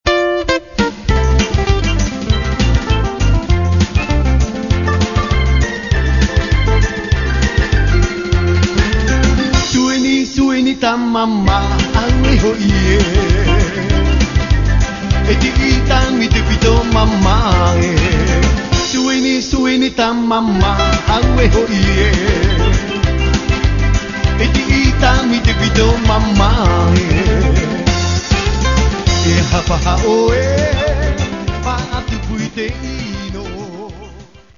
Musique Tahitienne